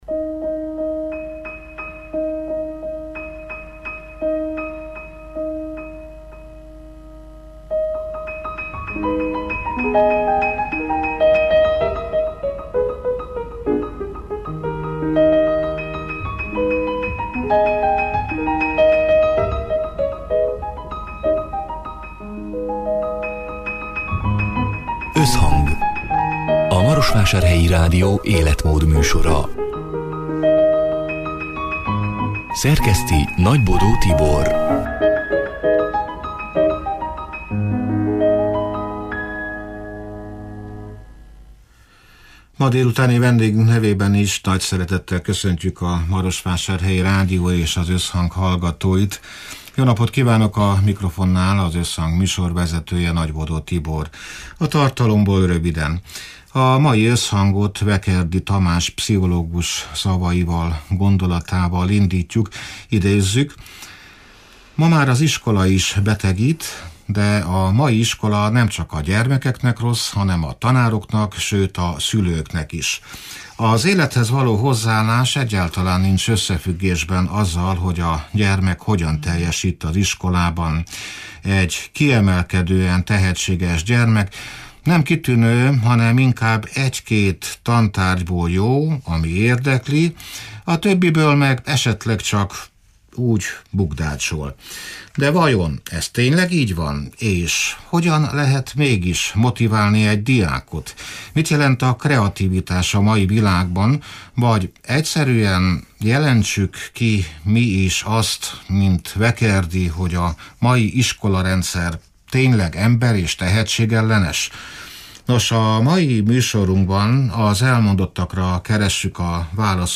(elhangzott: 2024. szeptember 11-én, szerdán délután hat órától élőben)